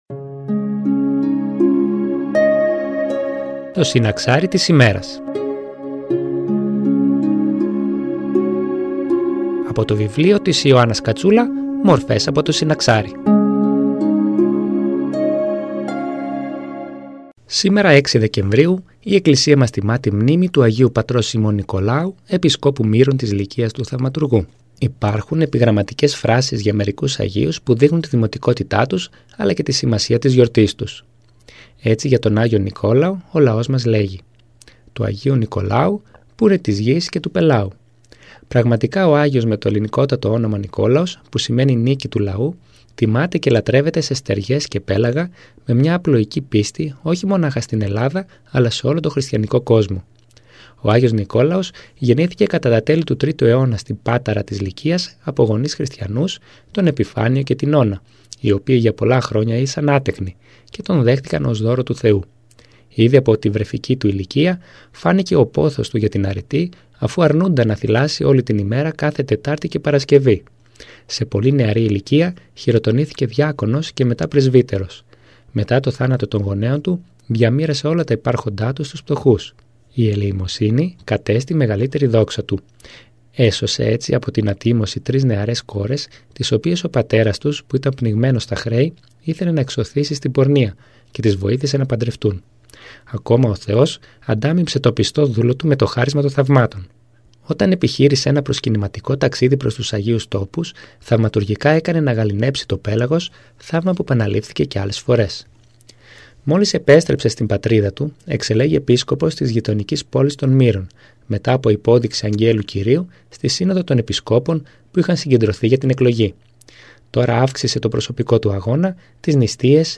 Η παρούσα ομιλία έχει θεματολογία «6 Δεκέμβριου – Άγιος Νικόλαος Μύρων».
Εκκλησιαστική εκπομπή